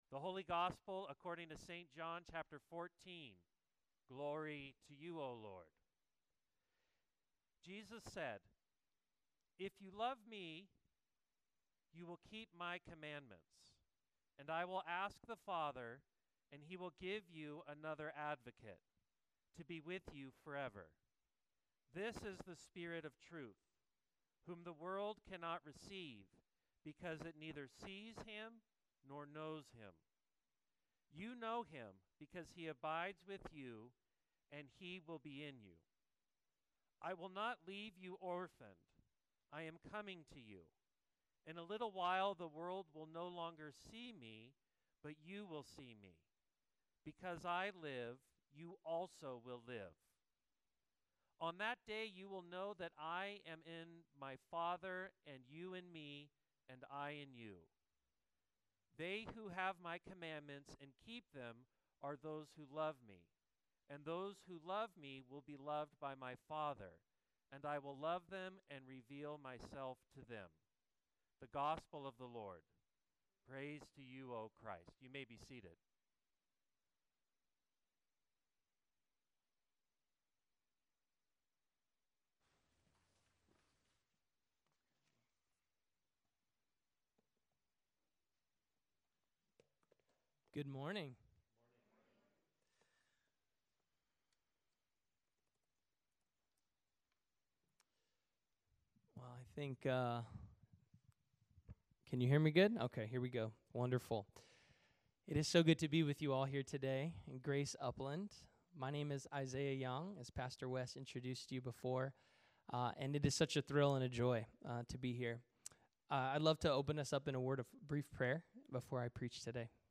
Sermon 05.18.25